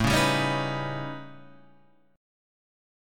A 9th Flat 5th